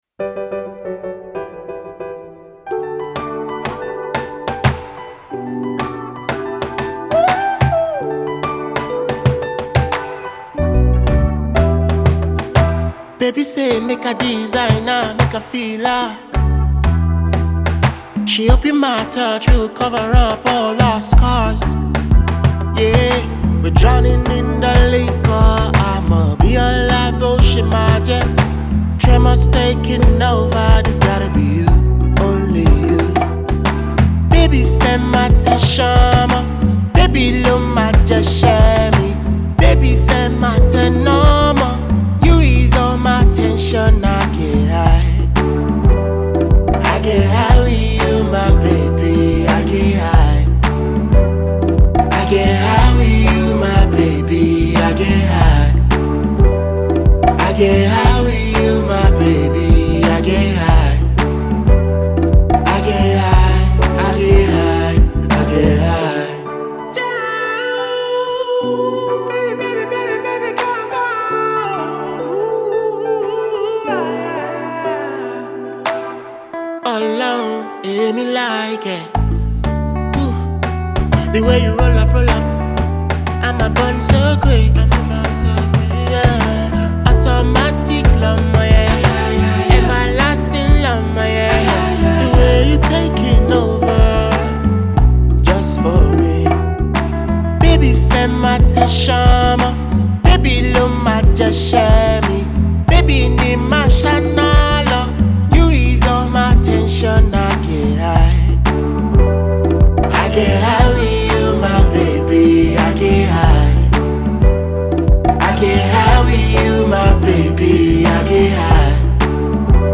Afro Fusion